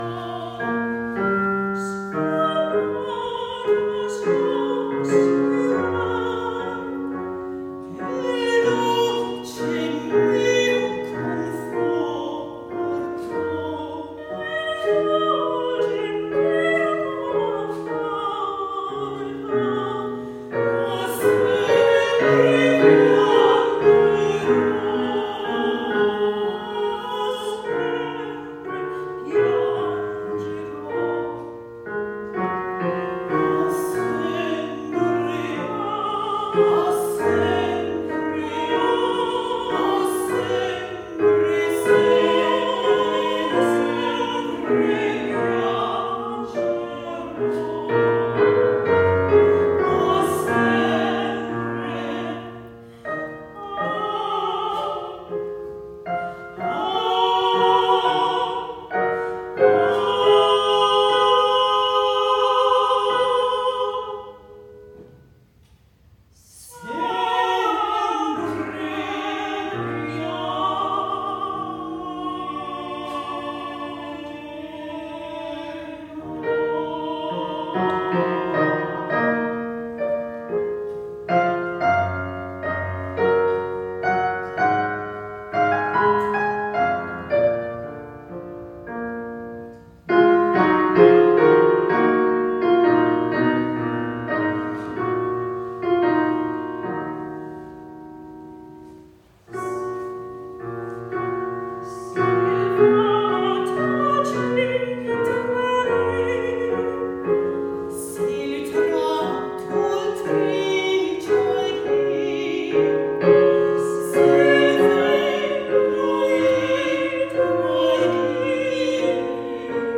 DUETS:
soprano
piano- live in concert 2023